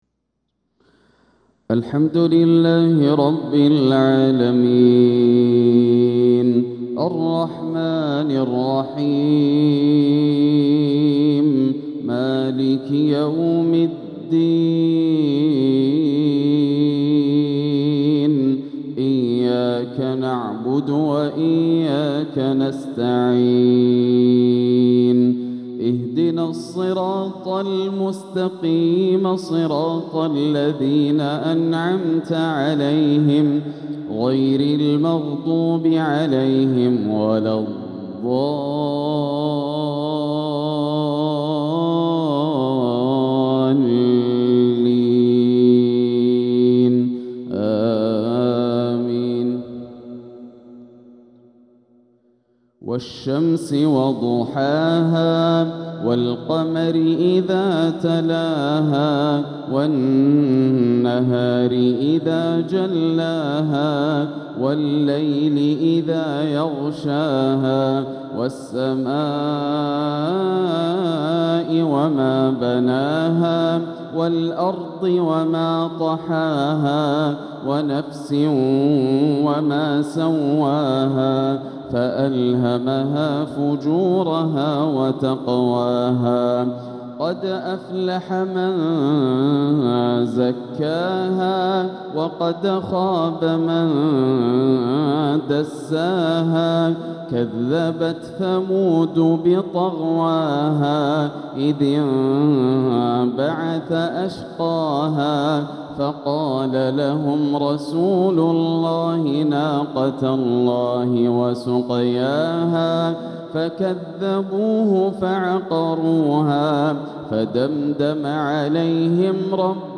تلاوة لسورتي الشمس والقارعة | مغرب الأحد 8-3-1447هـ > عام 1447 > الفروض - تلاوات ياسر الدوسري